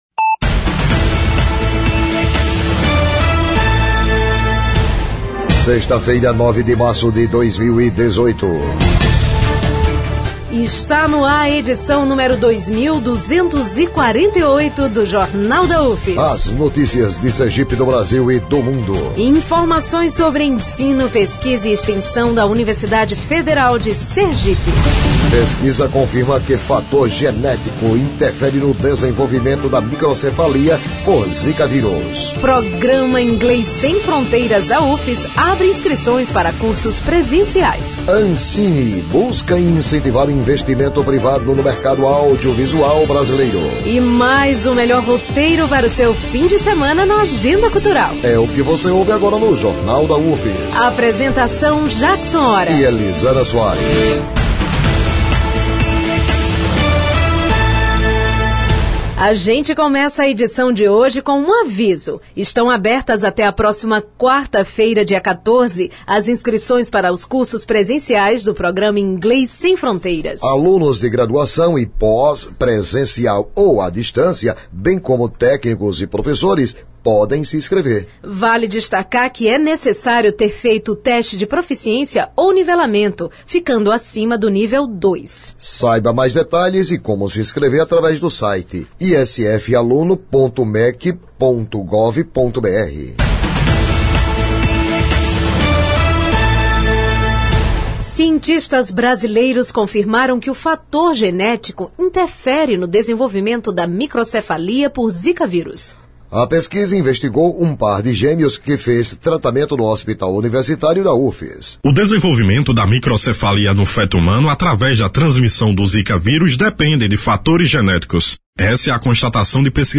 O noticiário vai ao ar às 11h, com reprises às 17h e 22h.